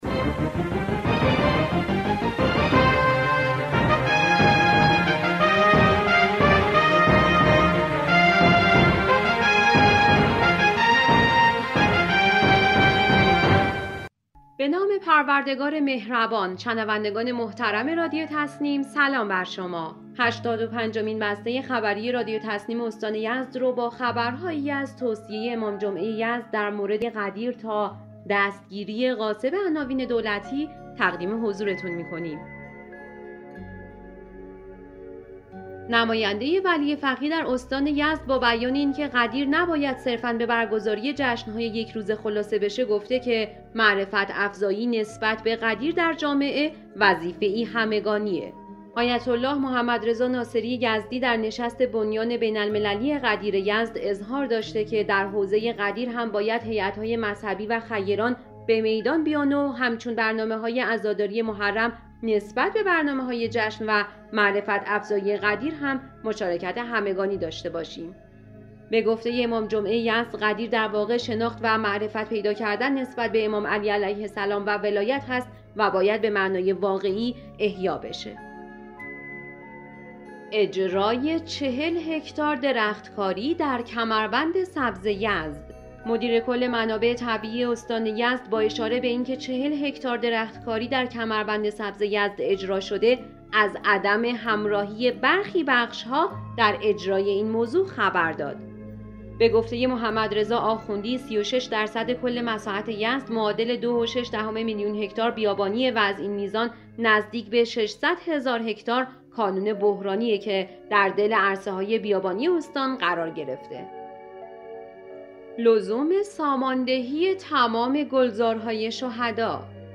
به گزارش خبرگزاری تسنیم از یزد, هشتاد و پنجمین بسته خبری رادیو تسنیم استان یزد با خبرهایی از توصیه امام جمعه یزد در مورد واقعه غدیر, اجرای 40 هکتار درختکاری در کمربند سبز یزد, لزوم ساماندهی تمام گلزارهای شهدا و دستگیری غاصب عناوین دولتی در یزد منتشر شد.